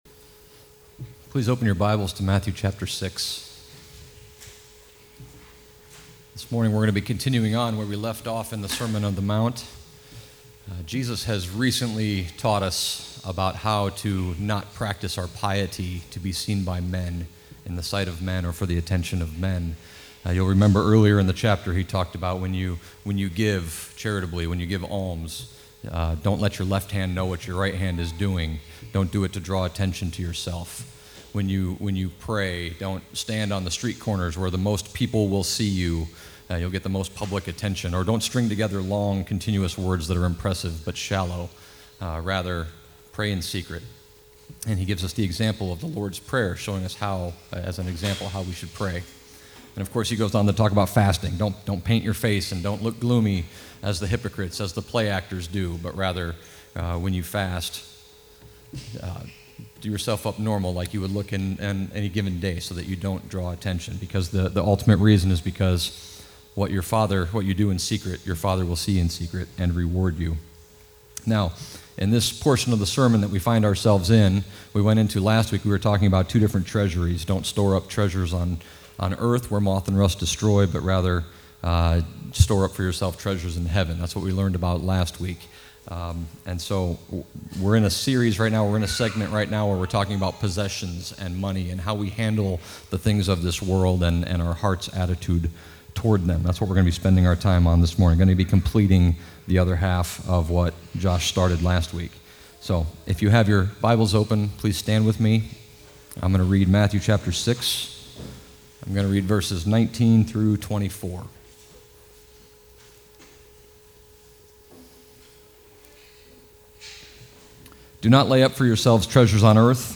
Covenant Reformed Fellowship Sermons